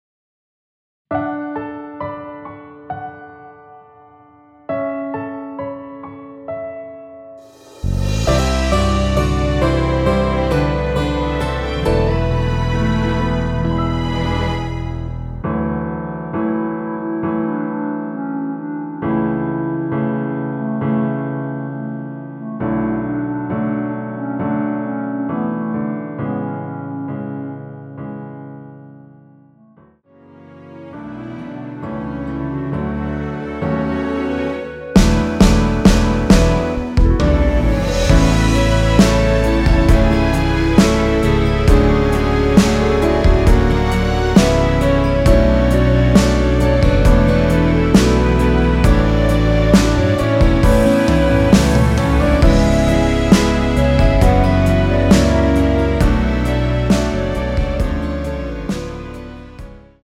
원키에서(-3)내린 1절앞+후렴으로 진행되는 멜로디 포함된 MR입니다.(미리듣기 확인)
멜로디 MR이란
앞부분30초, 뒷부분30초씩 편집해서 올려 드리고 있습니다.
중간에 음이 끈어지고 다시 나오는 이유는